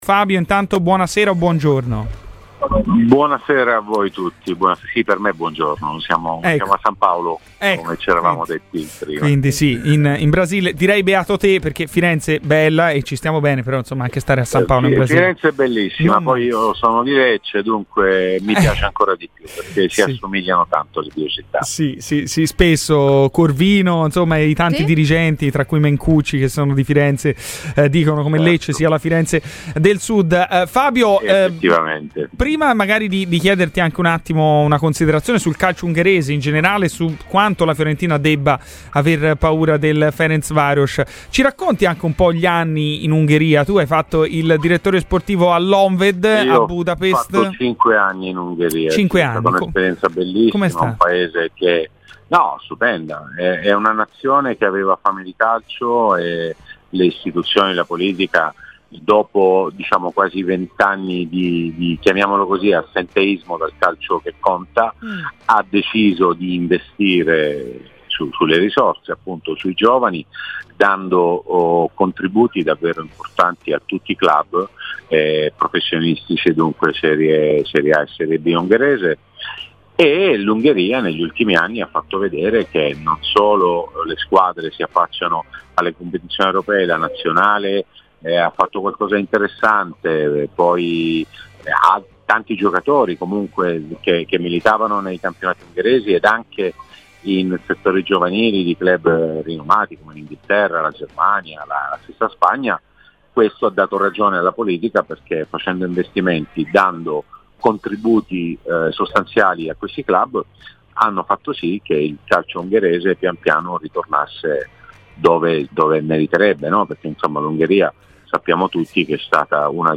Clicca sul podcast per ascoltare l'intervista completa.